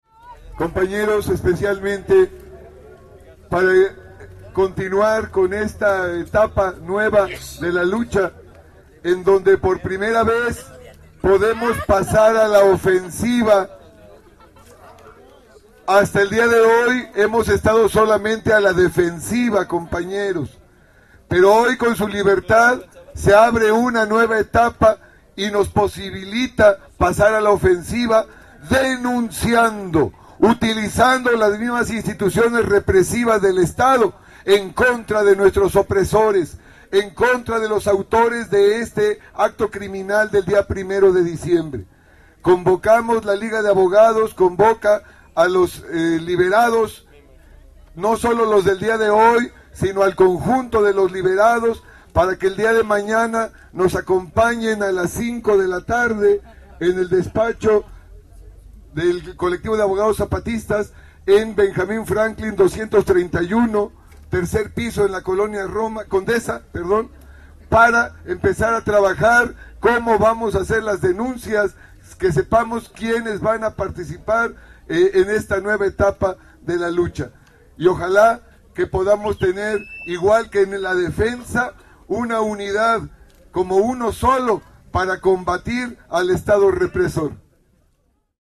Minutos más tarde dio inicio un acto donde “La Liga de Abogados 1 de Diciembre” y los ahora ex Presos Políticos, darían sus respectivos pronunciamientos.